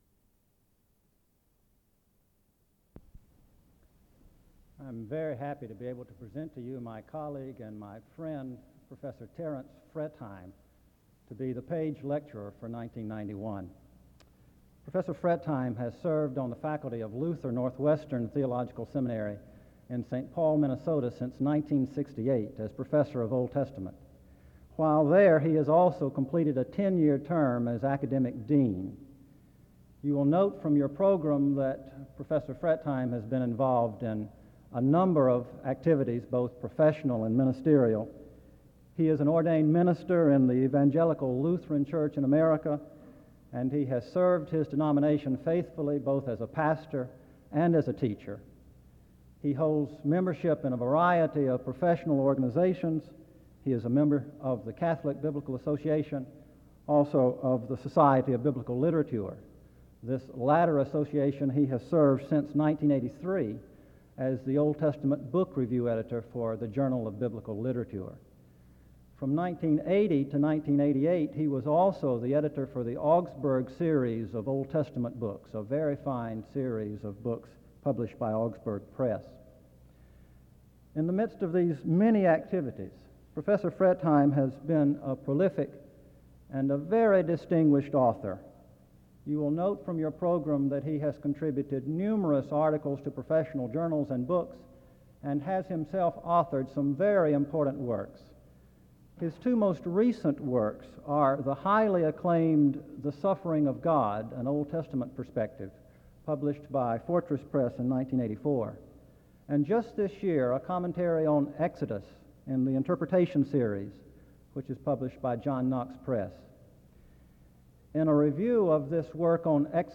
The audio was transferred from audio cassette. The service opens with an introduction of the speaker from 0:00-3:02.